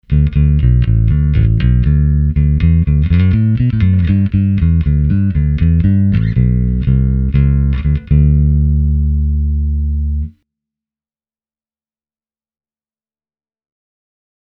Tältä basso kuulostaa soitettuna SansAmp Bass Driver DI:n läpi:
etumikrofoni – sormisoitto
lakland-skyline-44-60-neck-pu-finger.mp3